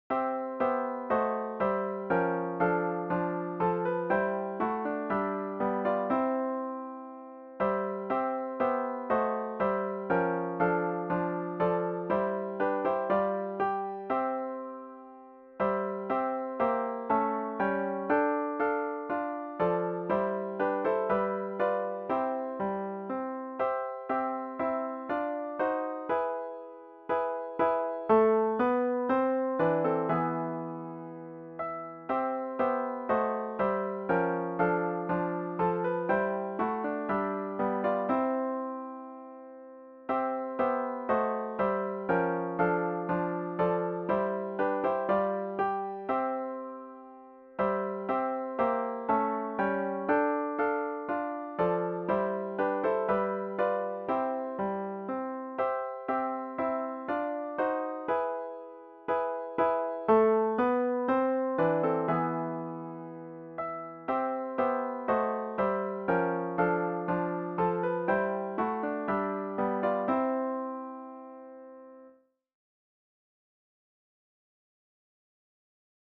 arranged for four guitars